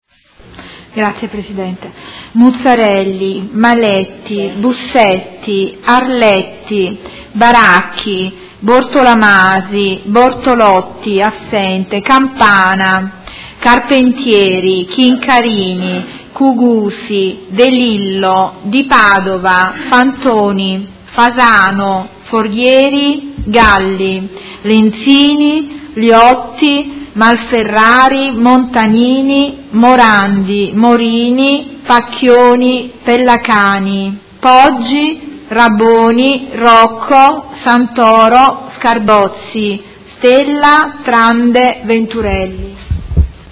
Appello
Segretario Generale